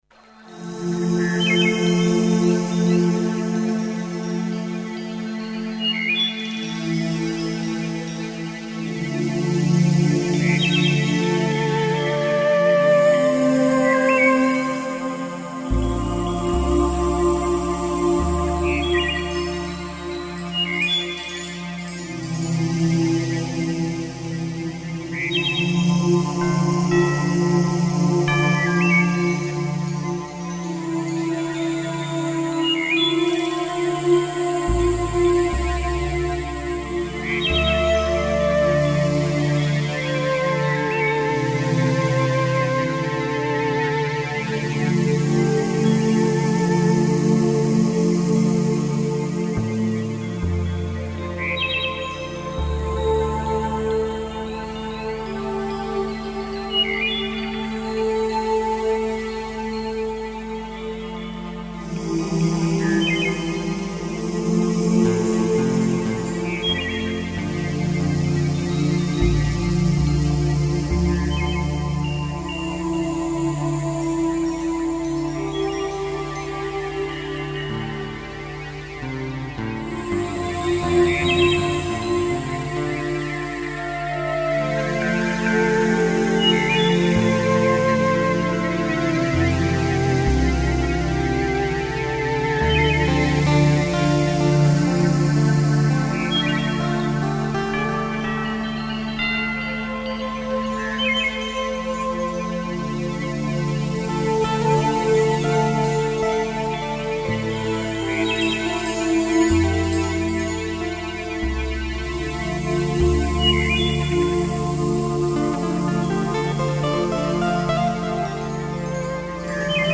Música para El Alma